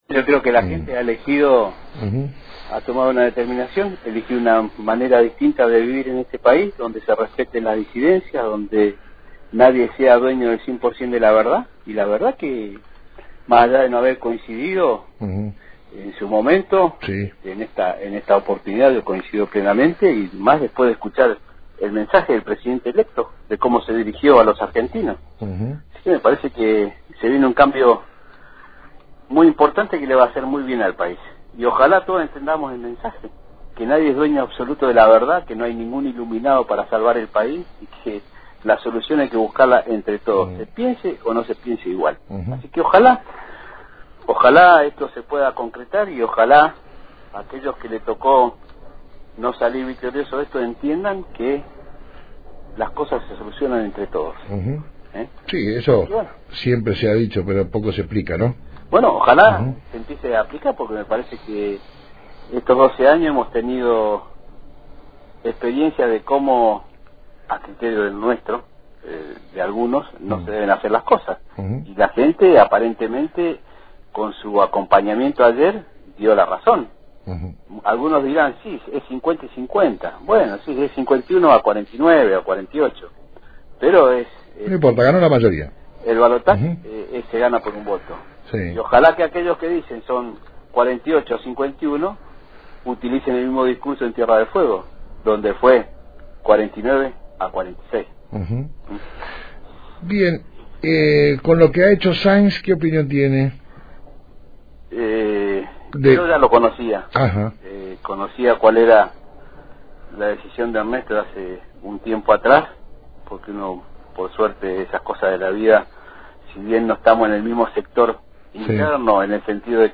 El legislador radical Pablo Blanco dialogó con Radio Fueguina y analizó el triunfo del frente Cambiemos en el balotaje de ayer, que coronó a Mauricio Macri como presidente de los argentinos.